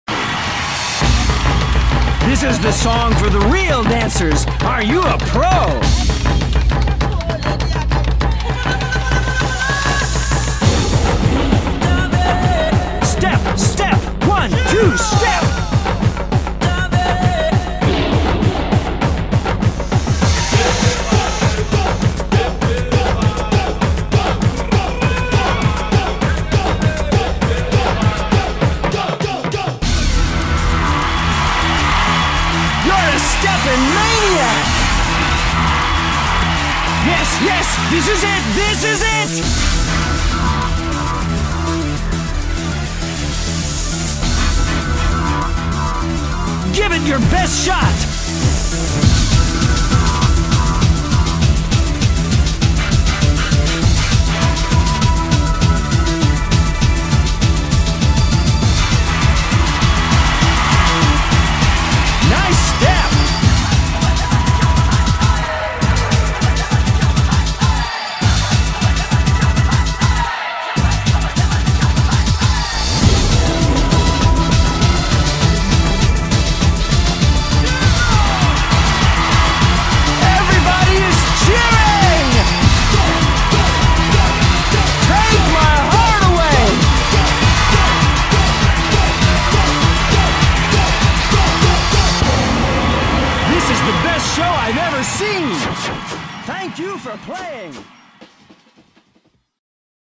8-bit remixed
BPM200